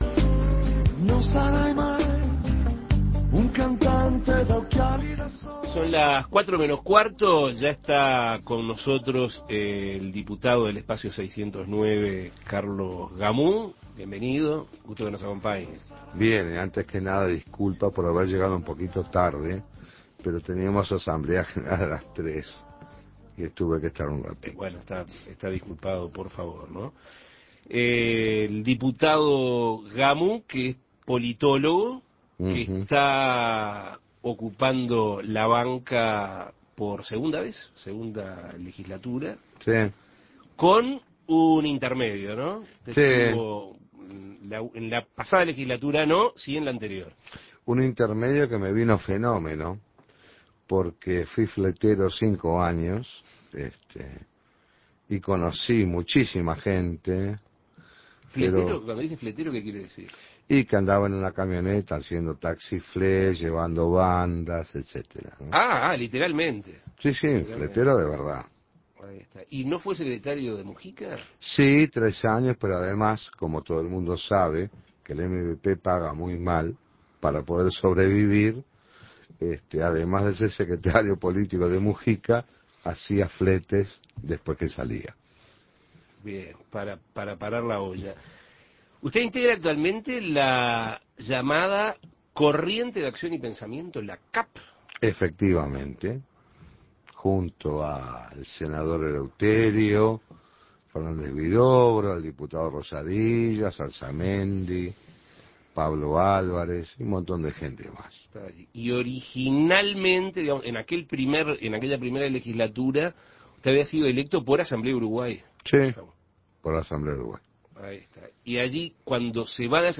Escuche la entrevista con el diputado oficialista Carlos Gamou